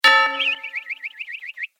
دانلود آهنگ تصادف 7 از افکت صوتی حمل و نقل
دانلود صدای تصادف 7 از ساعد نیوز با لینک مستقیم و کیفیت بالا